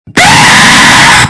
Extremely Loud Jumpscare Botão de Som
Games Soundboard0 views